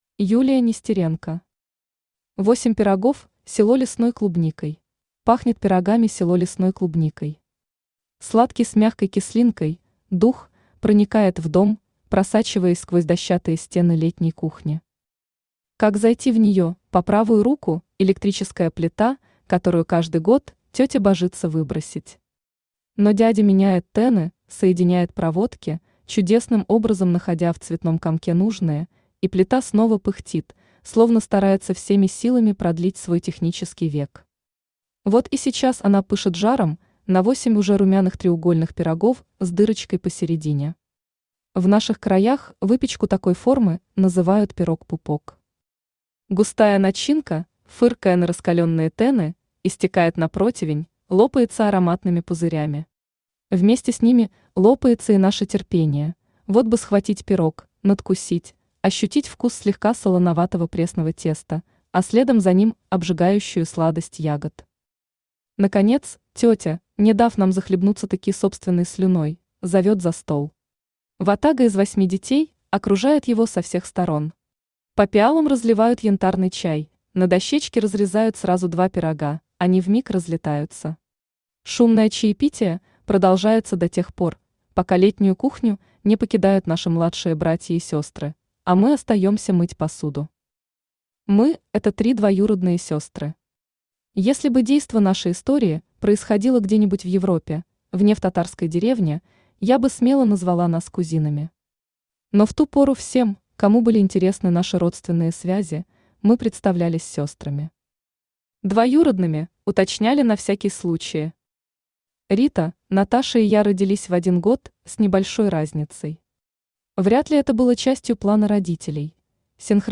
Aудиокнига Восемь пирогов с лесной клубникой Автор Юлия Нестеренко Читает аудиокнигу Авточтец ЛитРес.